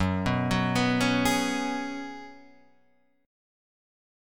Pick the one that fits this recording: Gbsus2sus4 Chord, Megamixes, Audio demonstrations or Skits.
Gbsus2sus4 Chord